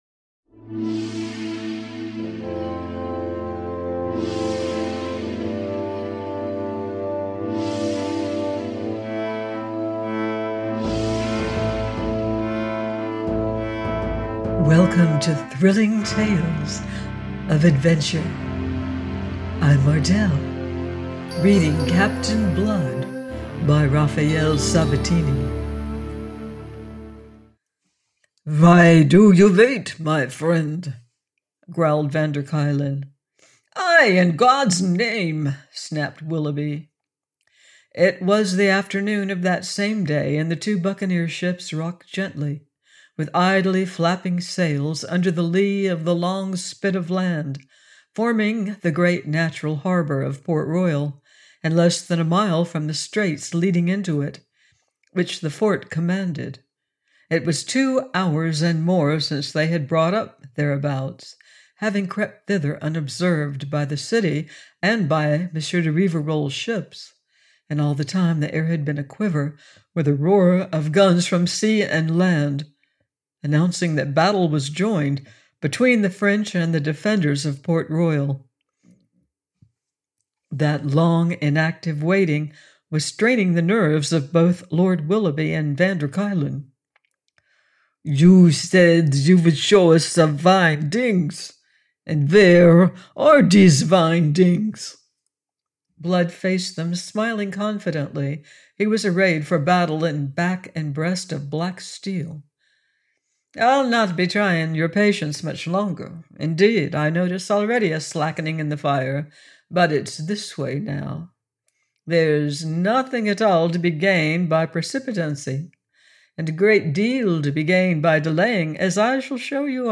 Captain Blood – by Raphael Sabatini - audiobook